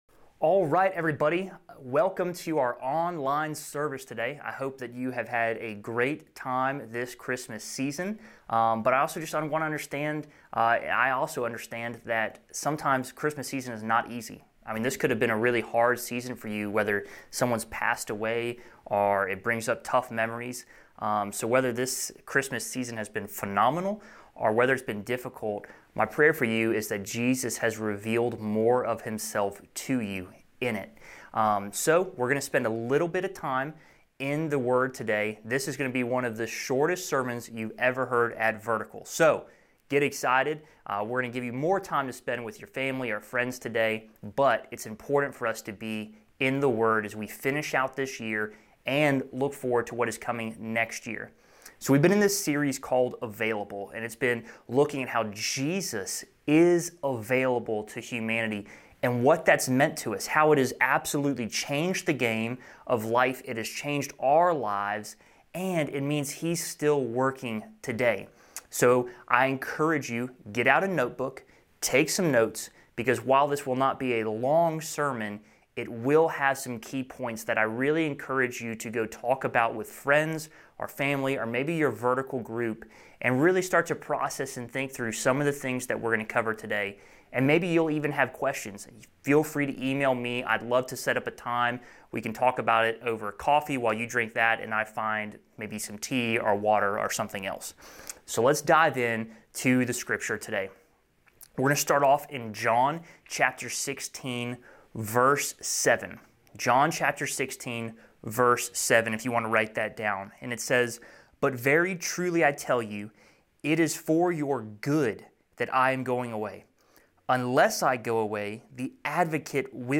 This is the final sermon in our series, "Available."